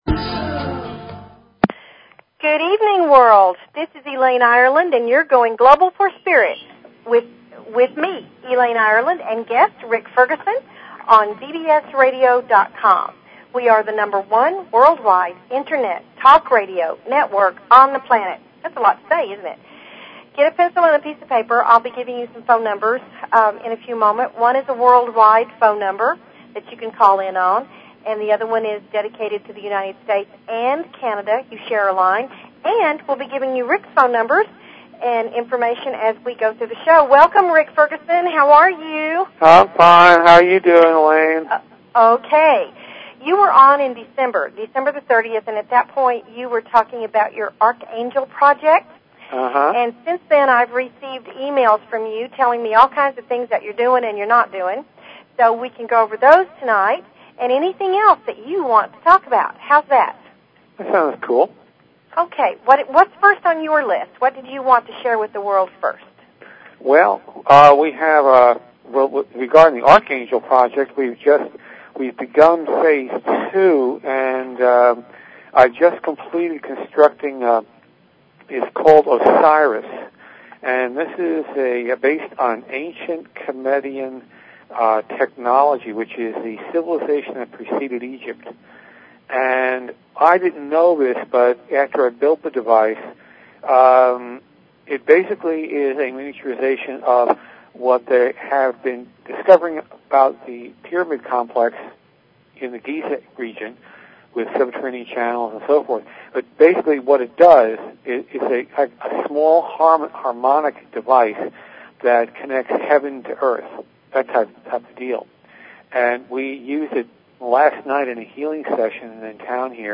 A variety of guests will be here to teach and share their wonders with you. They invite you to call in with your questions and comments about everything metaphysical and spiritual!"